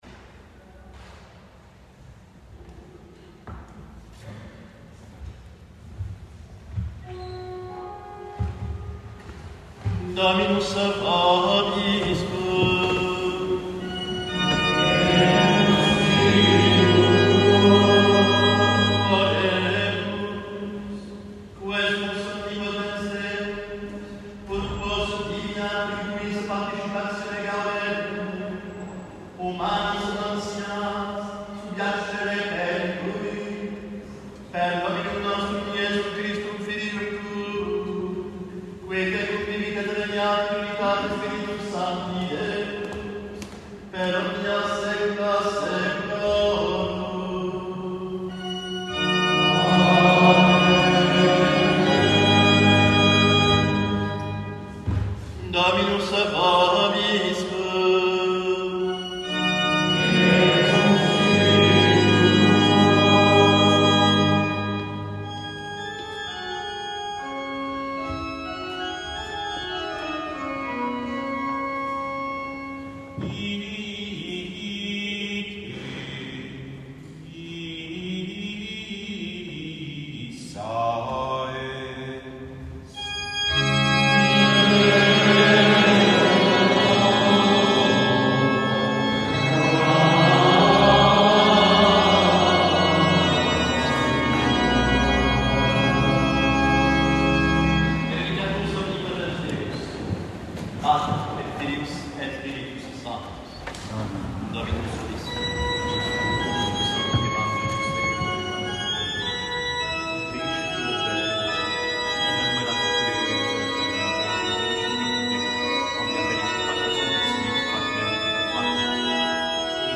Finally, here is an audio recording of the end of Mass.  You can hear what the church sounds like in the sanctuary for some of the prayers, the organ and the congregation singing the Salve Regina.